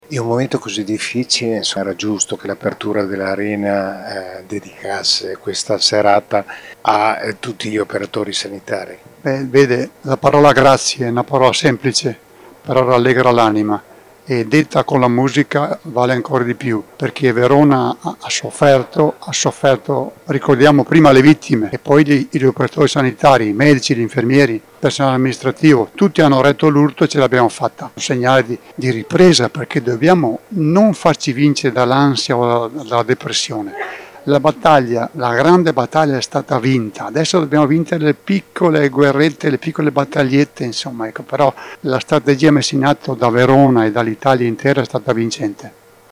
Al nostro microfono anche: